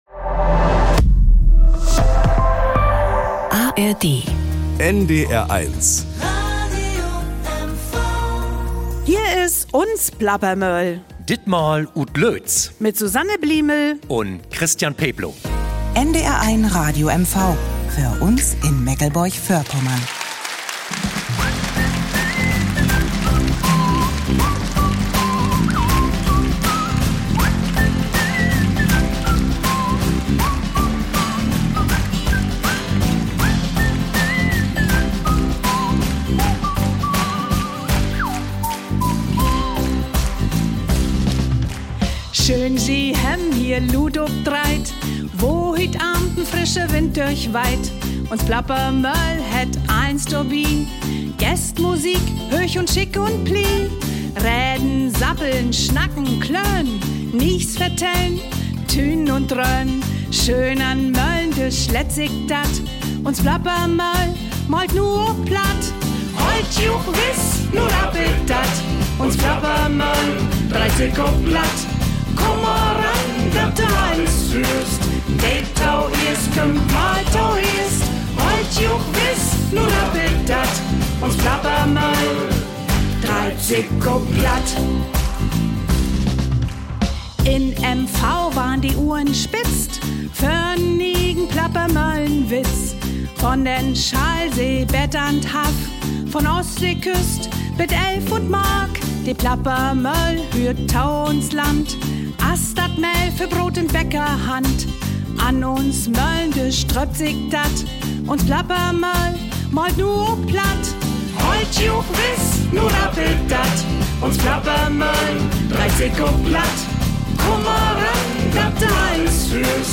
Wenn eine Landärztin aus dem Nähkästchen plaudert, ein weit gereister Segler von seinen Reisen erzählt und eine Universitätslehrerin die Ostseewellen zum Klingen bringt, dann ist Zeit für unsere Plappermoehl von NDR1 Radio MV.
Kulturkonsum, Handballer, Schülerinnen und Schüler, die Stadt Loitz - alle haben mitgeholfen, die Aufzeichnung zu einem echten Erlebnis zu machen - für die 250 Zuschauerinnen und Zuschauer in der Peenetalhalle, aber auch für die Plappermoehl-Crew. Musik: Die Breitling Stompers Mehr